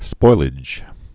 (spoilĭj)